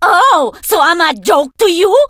diva_hurt_vo_01.ogg